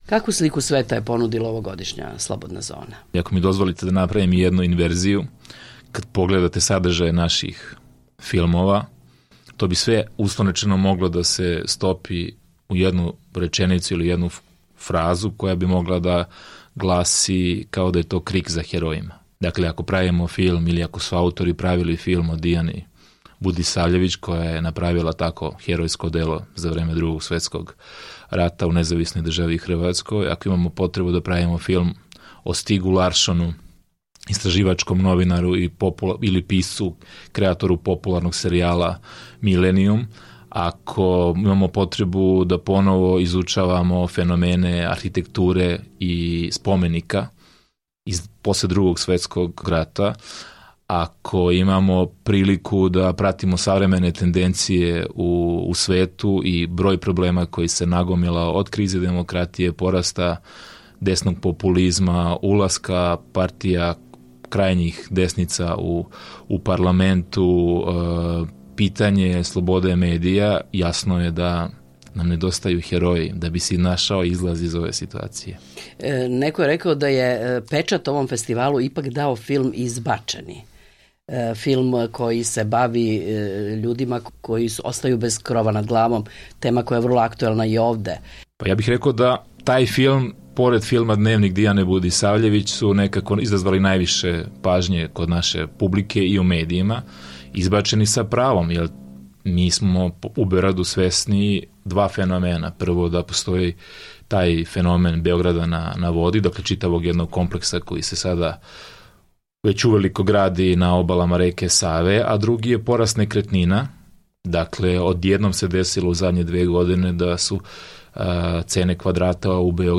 Embed Podijeli Intervju